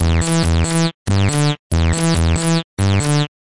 描述：合成器
Tag: 合成器